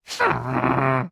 Minecraft Version Minecraft Version 25w18a Latest Release | Latest Snapshot 25w18a / assets / minecraft / sounds / entity / witch / ambient3.ogg Compare With Compare With Latest Release | Latest Snapshot
ambient3.ogg